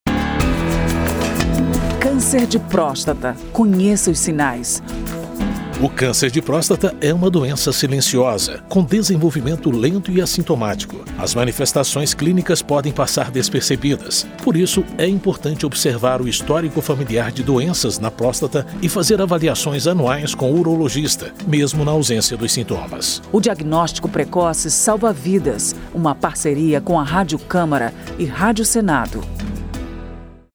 spot-cancer-de-prostata-01-parceiras.mp3